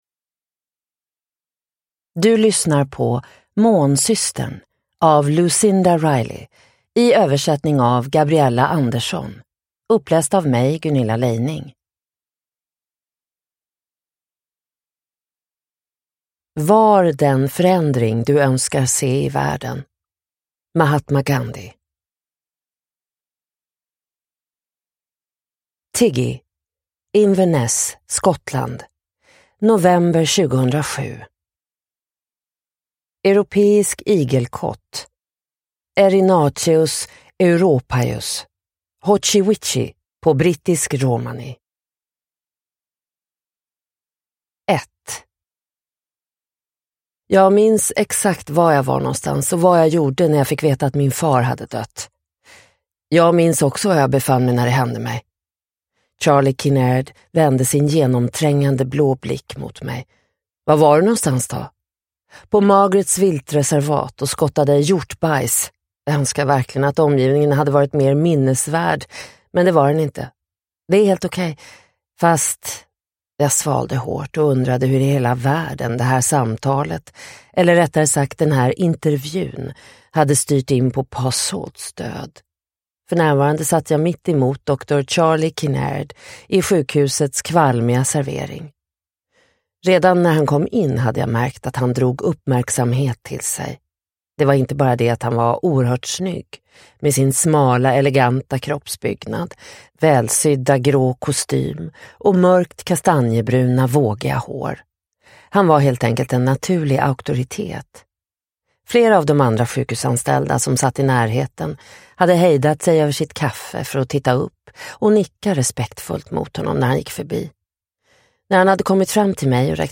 Månsystern: Tiggys bok – Ljudbok – Laddas ner